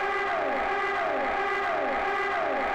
Index of /90_sSampleCDs/AMG - Prototype Drum & Bass/REX Files/Mini Tracks/Jump Up
Jump Up Siren.WAV